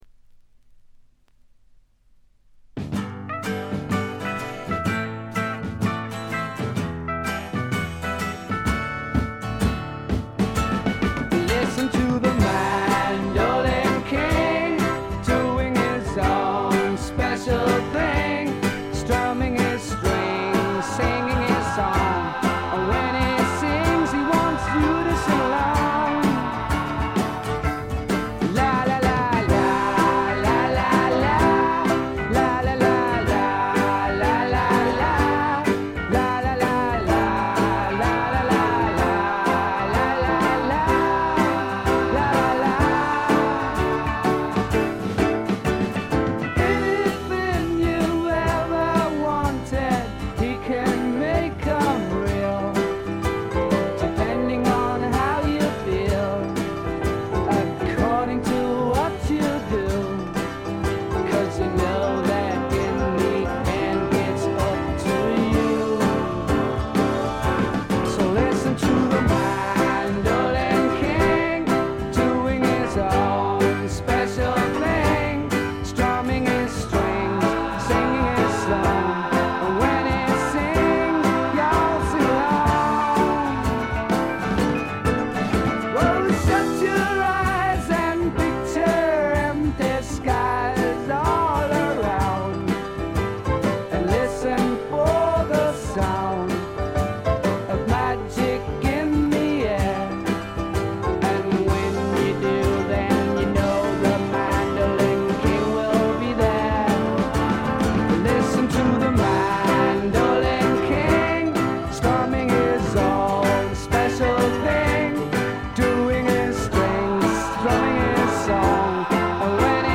これぞ英国流フォークロックとも言うべき名作です。
試聴曲は現品からの取り込み音源です。